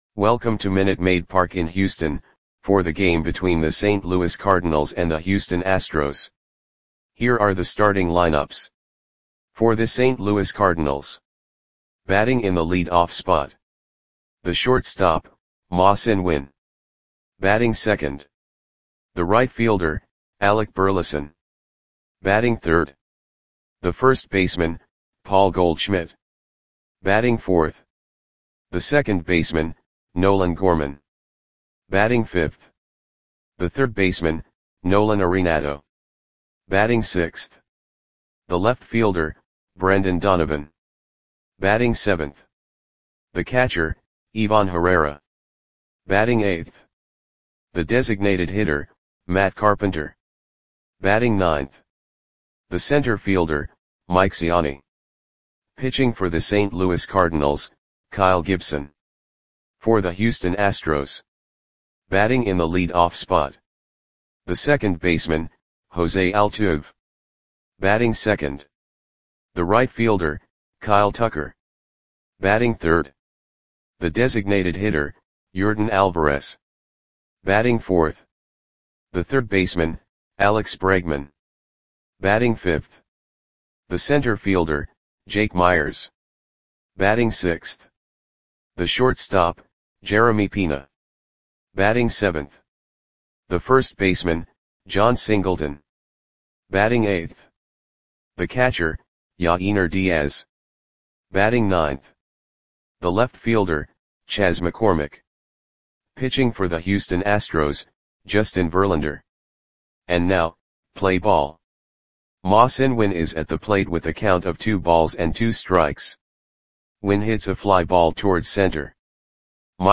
Audio Play-by-Play for Houston Astros on June 3, 2024
Click the button below to listen to the audio play-by-play.